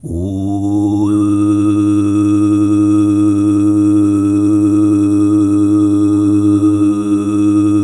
TUV2 DRONE03.wav